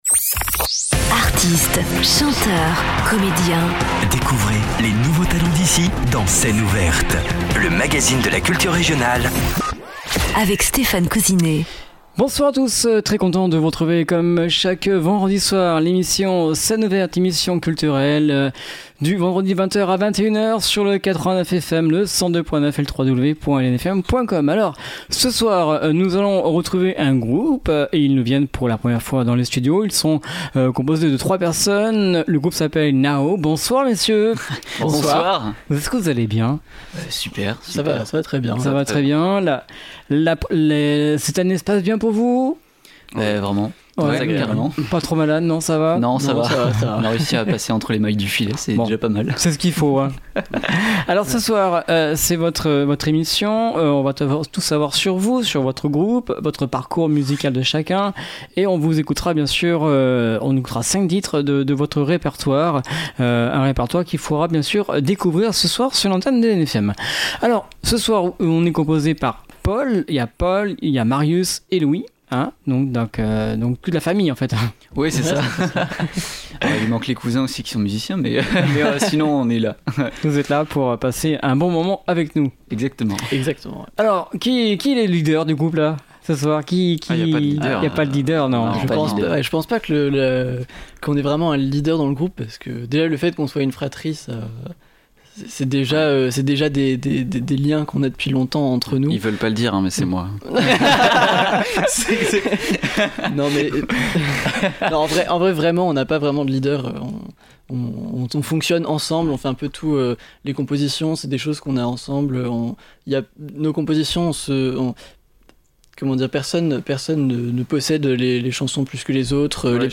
batterie
lignes de basse sophistiquées
riffs de guitare puissants
D’une voix à la fois sensible et énergique